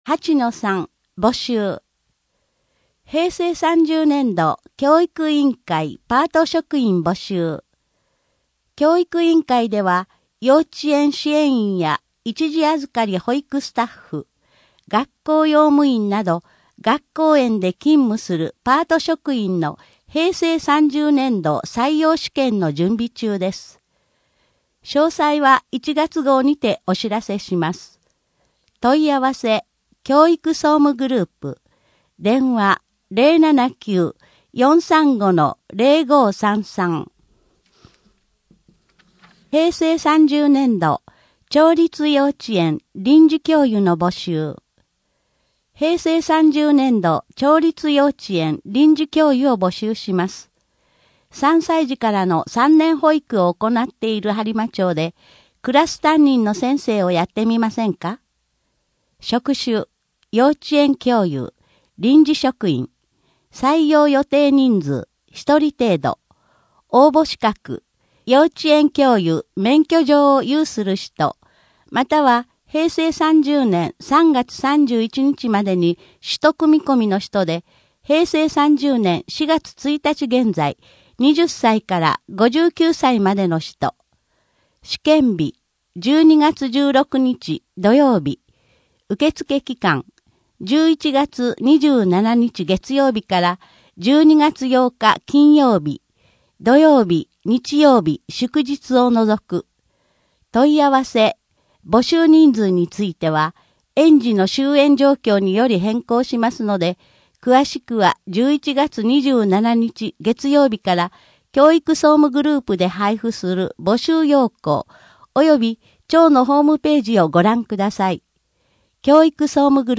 声の「広報はりま」12月号
声の「広報はりま」はボランティアグループ「のぎく」のご協力により作成されています。